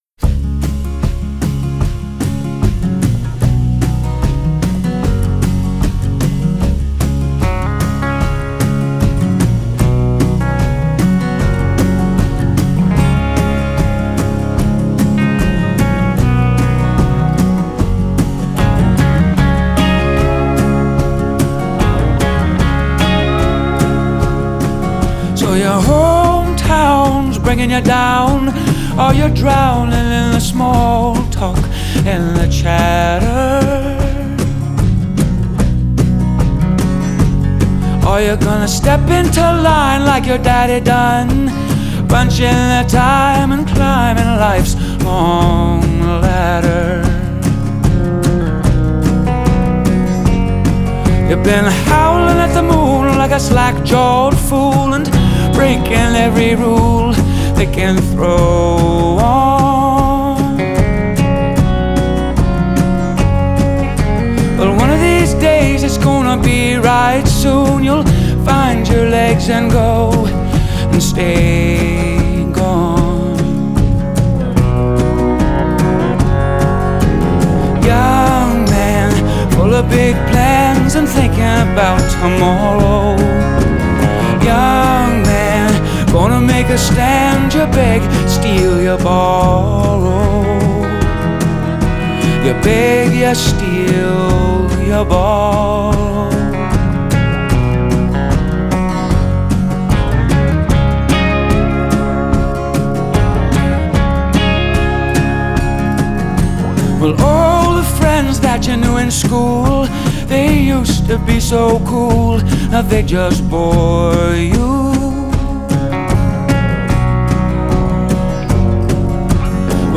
rolling country song